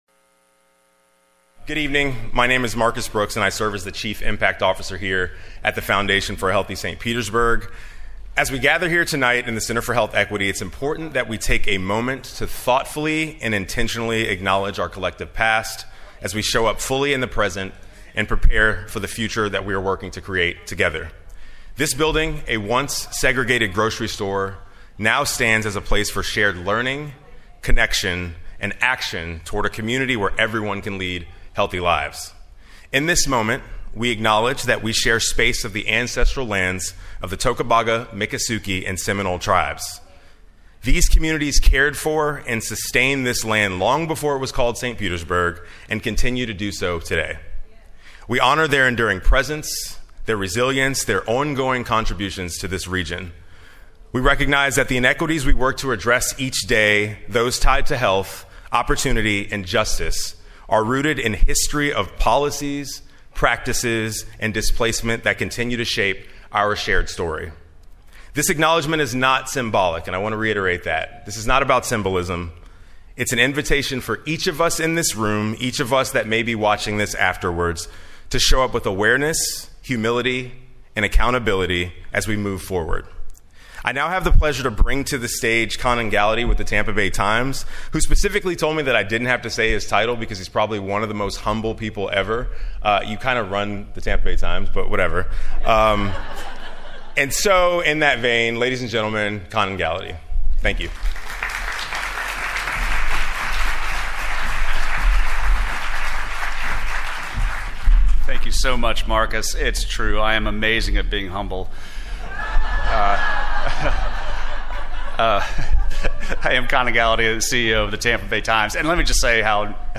Presented by Tampa Bay Times, in partnership with Foundation for a Healthy St. Petersburg In an era when many feel isolated and emotionally overwhelmed, how can we listen and connect?